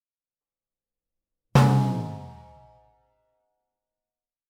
Звуки анимации
Мульт удар